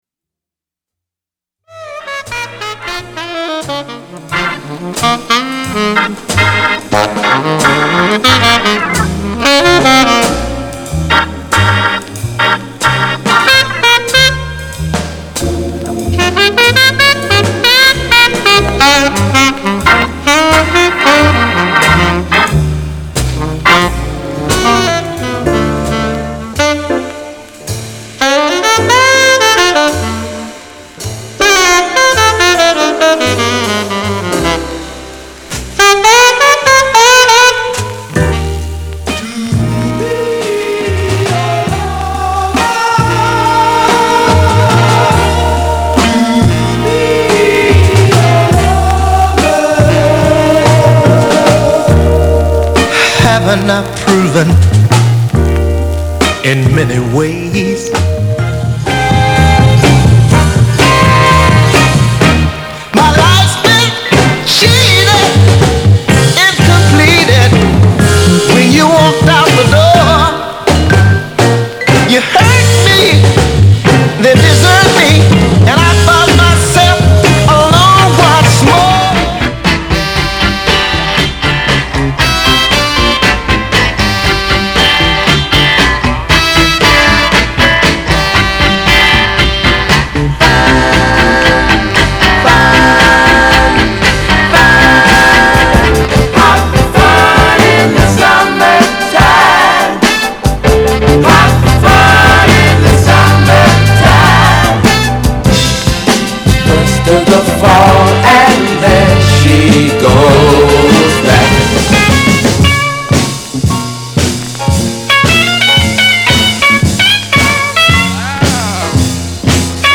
R&B、ソウル
/盤質/両面やや傷あり再生良好/US PRESS